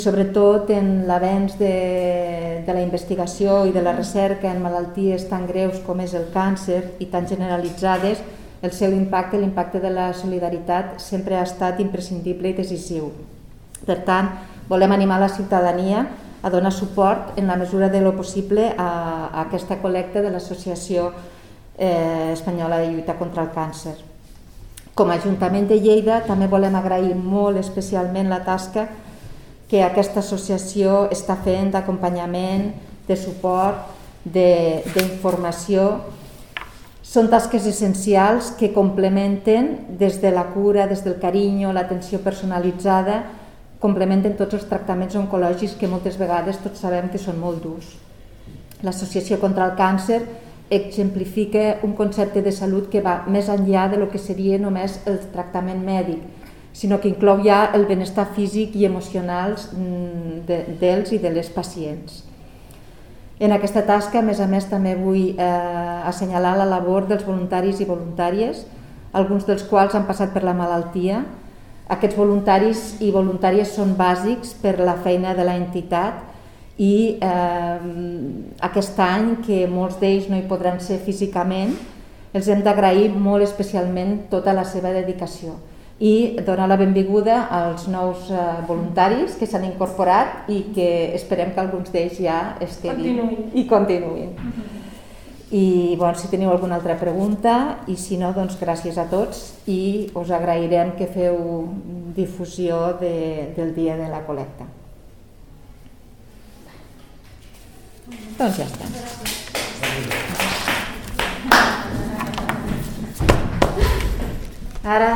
tall-de-veu-de-la-tinent-dalcalde-i-regidora-de-salut-publica-montse-pifarre